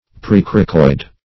Search Result for " precoracoid" : The Collaborative International Dictionary of English v.0.48: Precoracoid \Pre*cor"a*coid\, n. (Anat.)
precoracoid.mp3